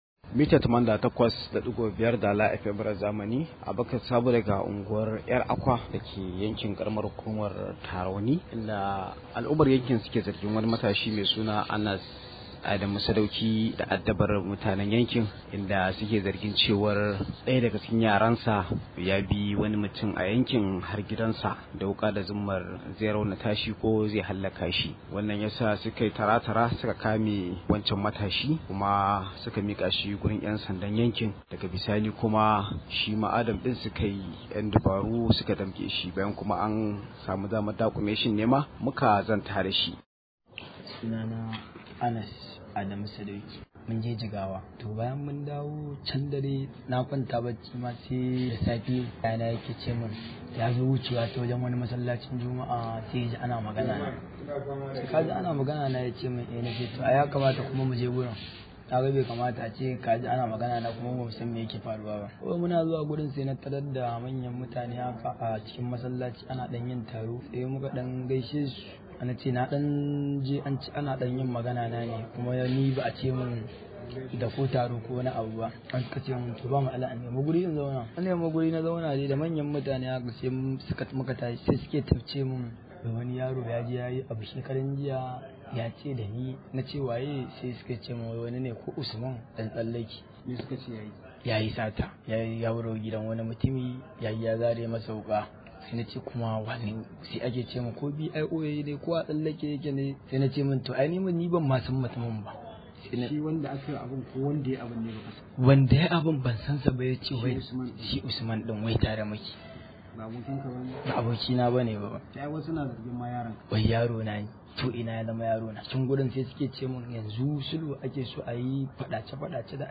Rahoto: Mun kai matashin wajen ‘yan sanda ne saboda ya addabi al’umma – Unguwar ‘Yar Akwa